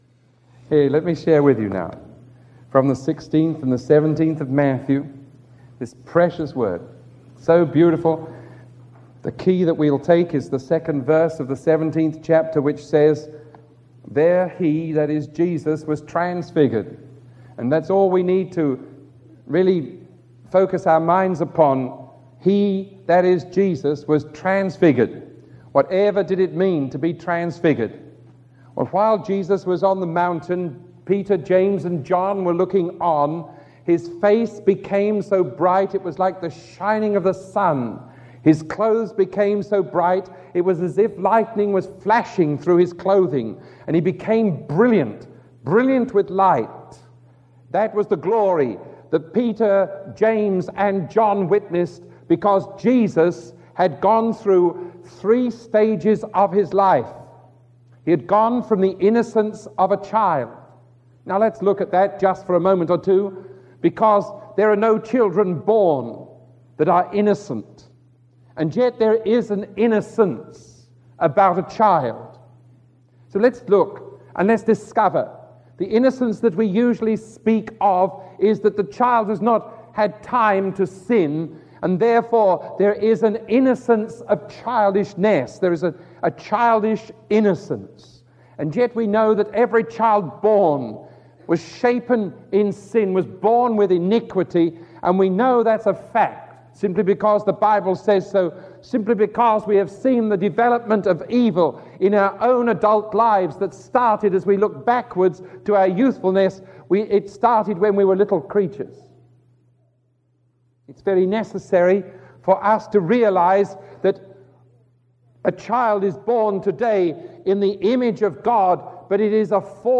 Sermon 1029B recorded on October 20